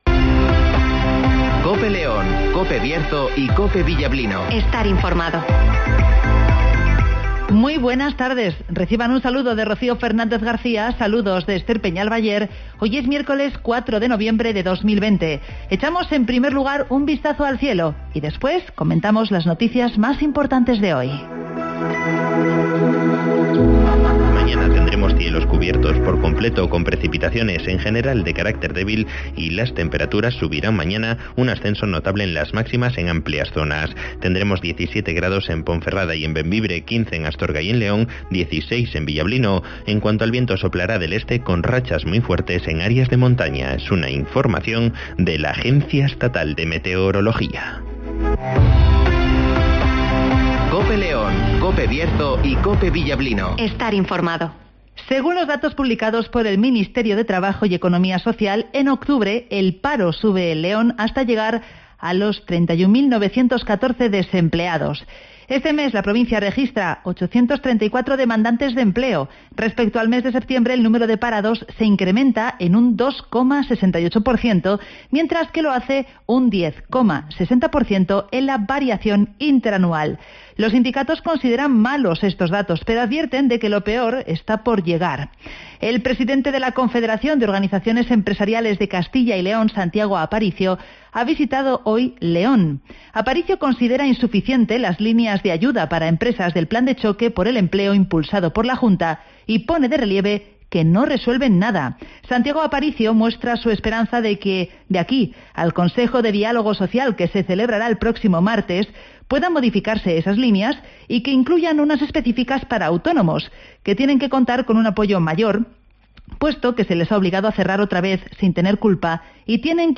INFORMATIVOS
Conocemos las noticias de las últimas horas del Bierzo y León, con las voces de los protagonistas.